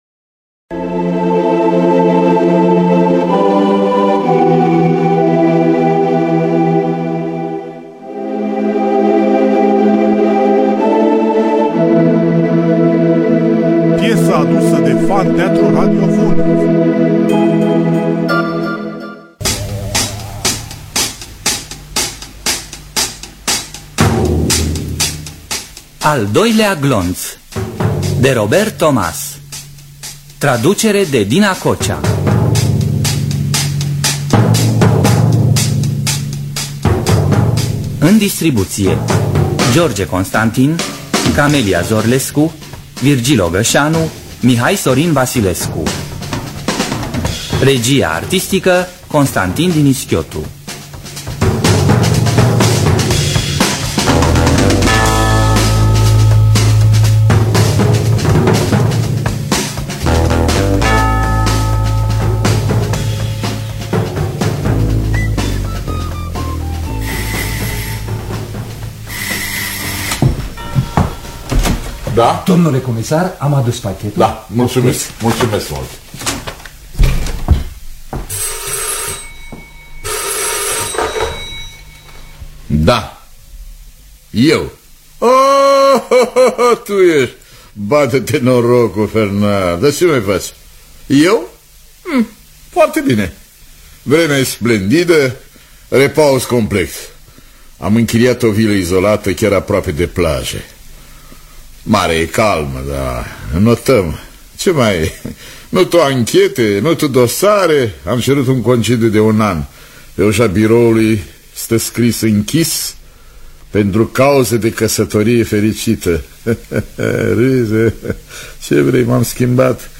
“Al doilea glonț” de Robert Thomas – Teatru Radiofonic Online